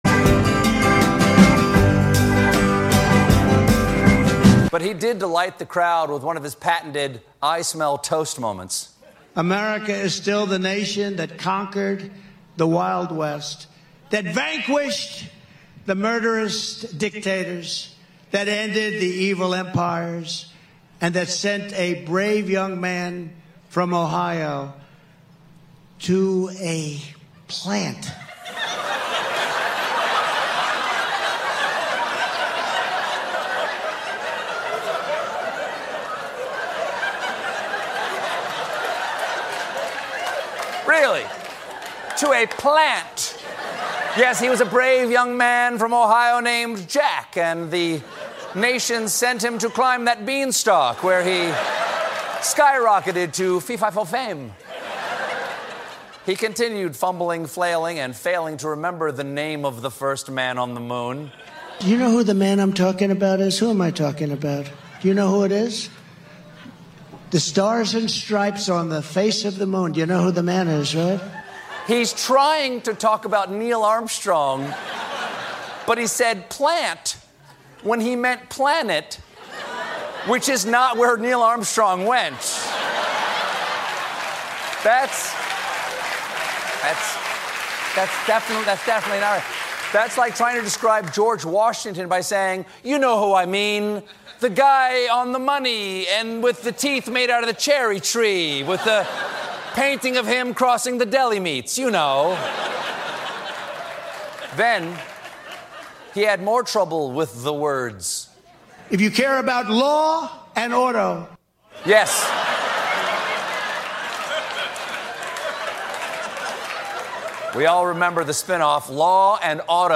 Contributions from many WGXC programmers.